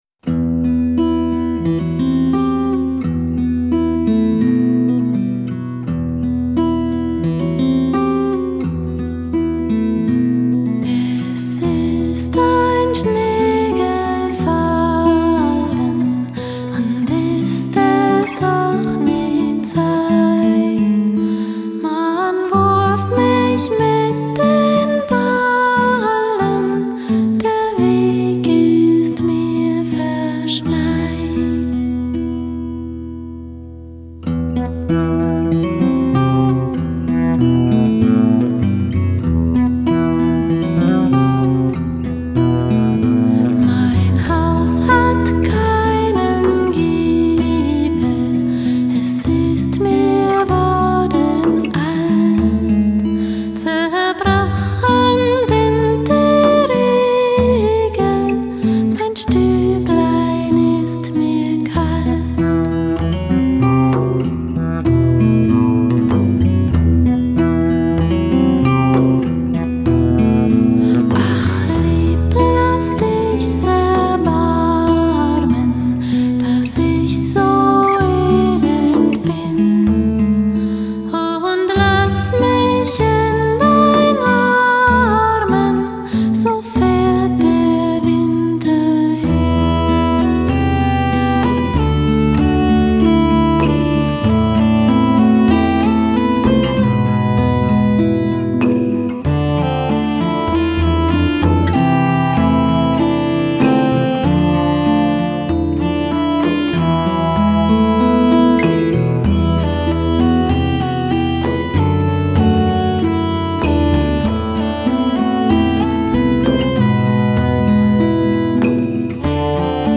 在德国民谣的基础上大量使用了苏格兰、爱尔兰、
女声清冽，旋律淡雅，
看到淡雅的旋律象雾气一样笼罩身体，